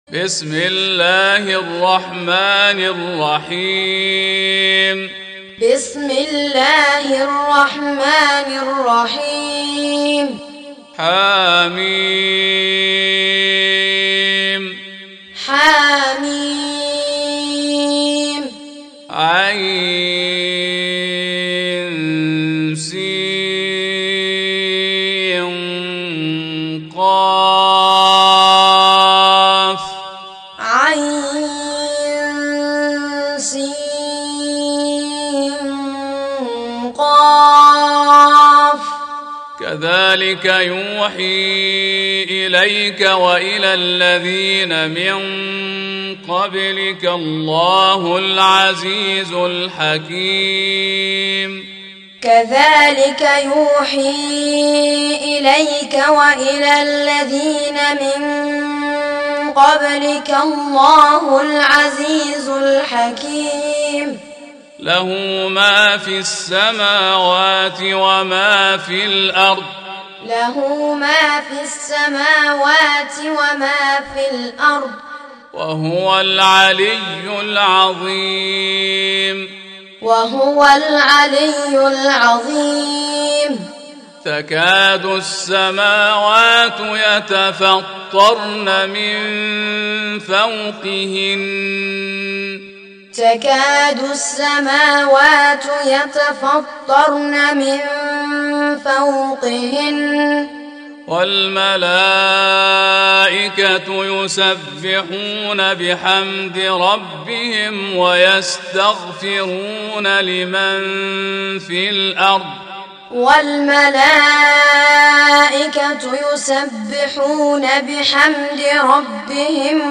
Tutor M Siddiq Al-Minshawi
Surah Sequence تتابع السورة Download Surah حمّل السورة Reciting Muallamah Tutorial Audio for 42. Surah Ash-Sh�ra سورة الشورى N.B *Surah Includes Al-Basmalah Reciters Sequents تتابع التلاوات Reciters Repeats تكرار التلاوات